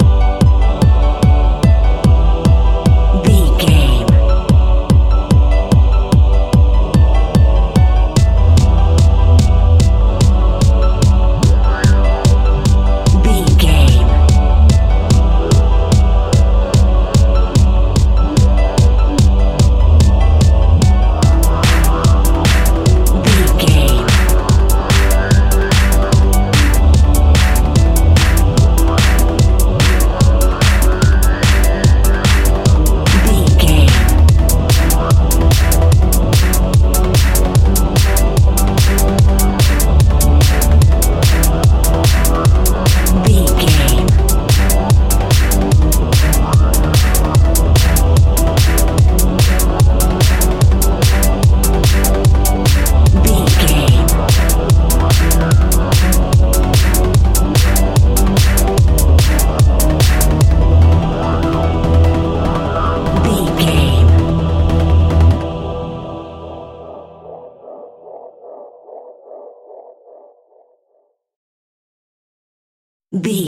Aeolian/Minor
Fast
futuristic
hypnotic
epic
dark
drum machine
synthesiser
piano
electronic
uptempo
synth leads
synth bass